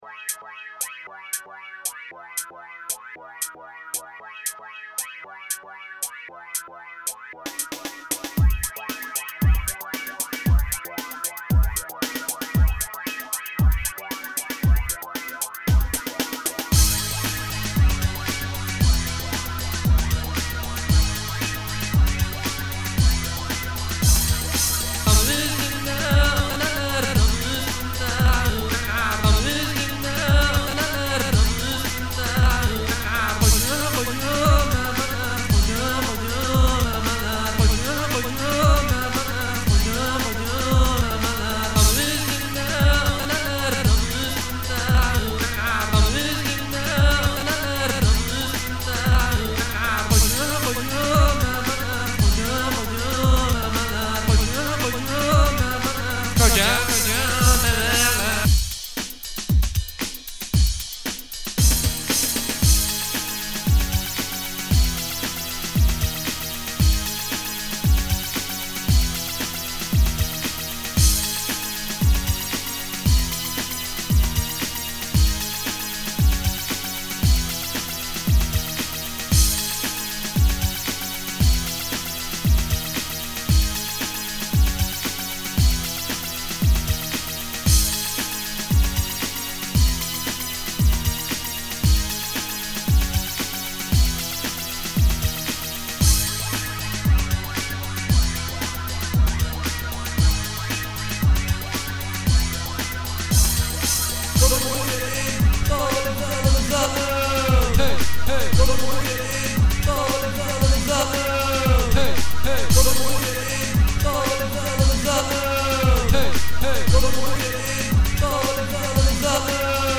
Neyse biz bunları konuşurken konu konuyu açtı ve benim zamanında sözlü olarak Fast Tracker'da yaptığım bir şarkıya geldi. 2 yıl kadar önce 7DX Party'e 2 adet xm müzikle katılmıştım ve not olarak söylemiştim ki "eğer 2 şarkıyla katılabiliyorsak ikisini birden alın yoksa Millenium isimli şarkıyı kabul edin.